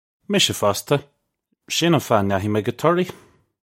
Misha fosta. Shin un fah uh nya-hee may go Torree. (U)
This is an approximate phonetic pronunciation of the phrase.